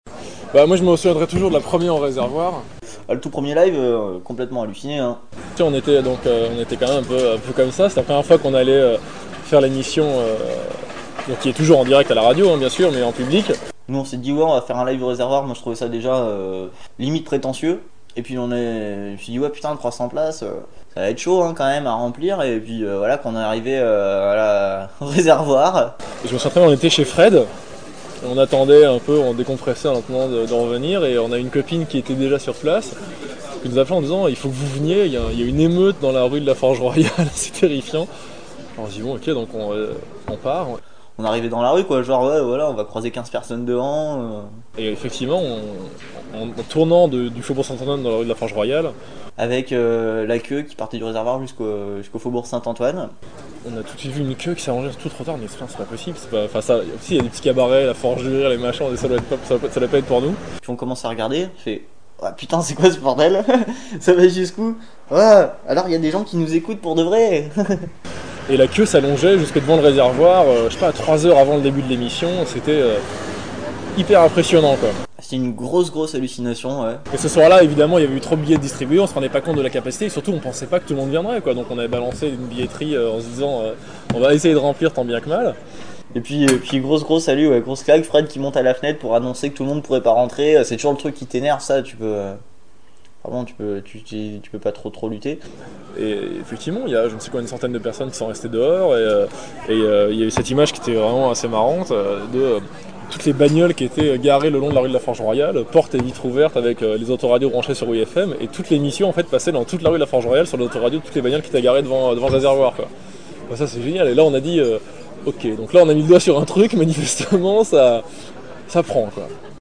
Le coup de génie, ce qui a permit un palier supplémentaire, c'est l'instauration d'émissions régulières en direct, en public, et hors de la radio.
Le public chahuteur n'était pas là non plus pour aider à la compréhension de l'histoire.
Le premier live,
mix-premierreservoir.mp3